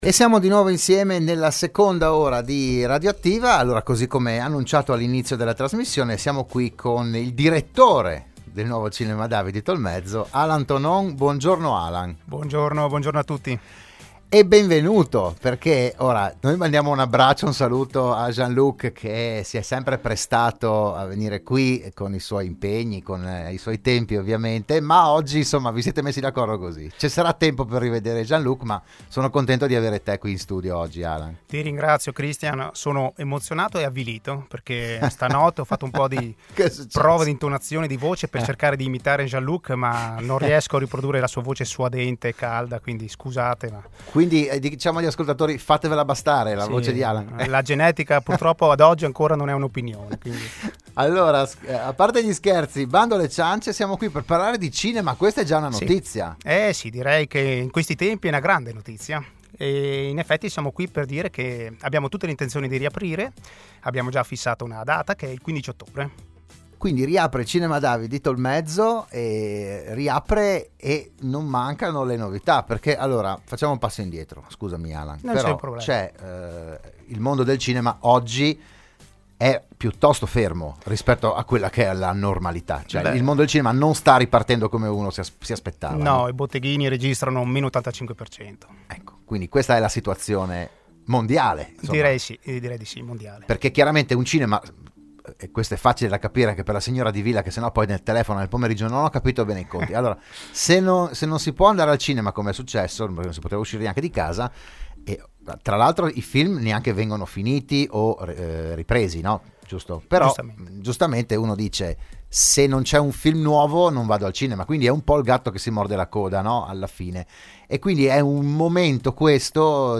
“RadioAttiva“, la trasmissione di Radio Studio Nord